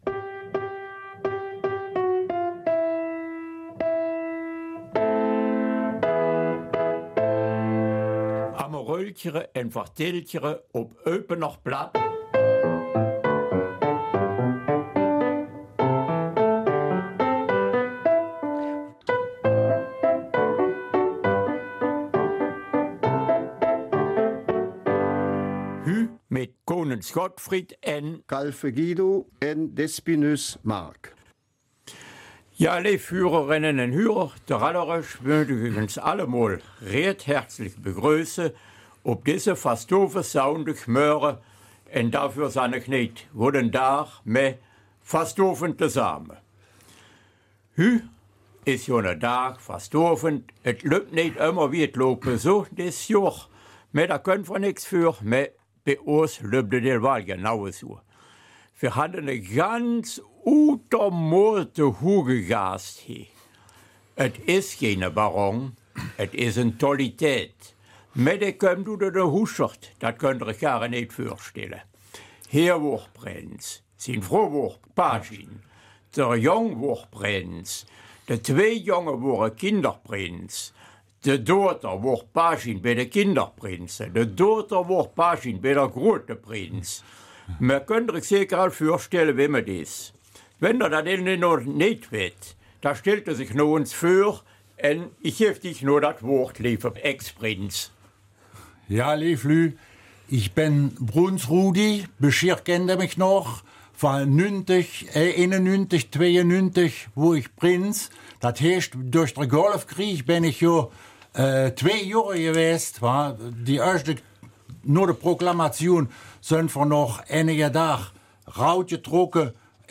Eupener Mundart: Erinnerungen an Karneval